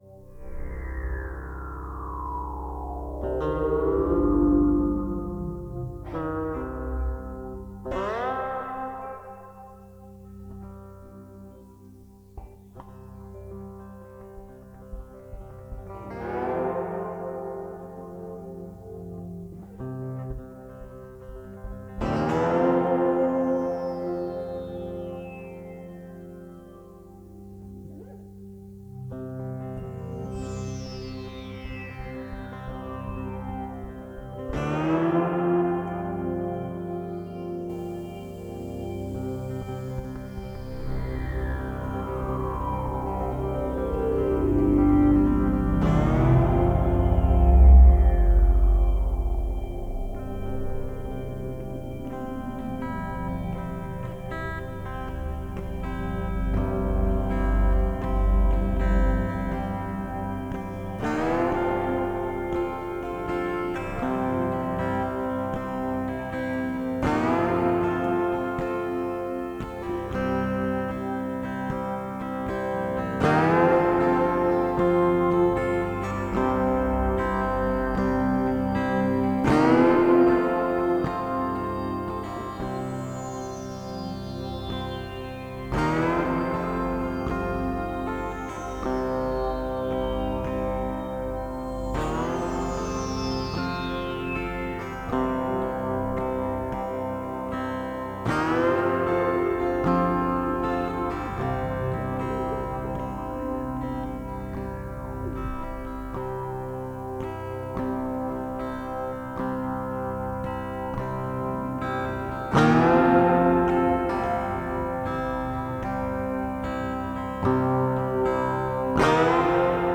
Dark amtosphere. Sound Effects pending with guitar.